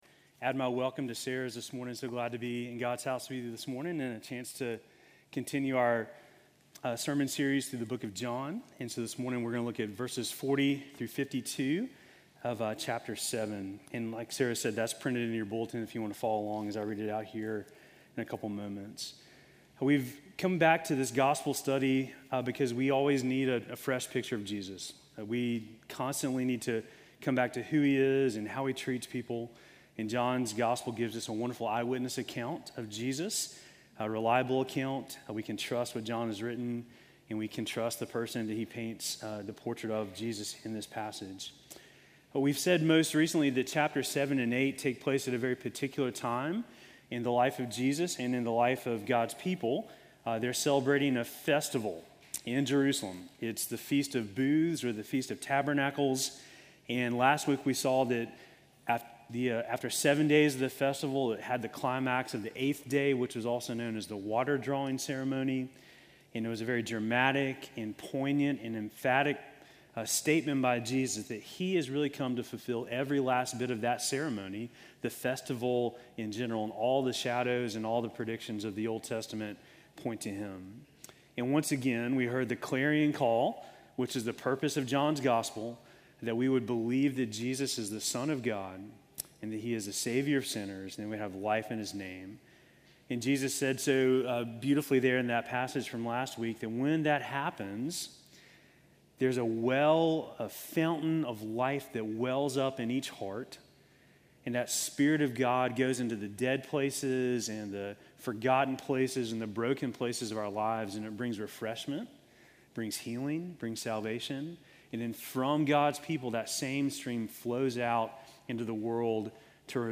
Sermon from August 31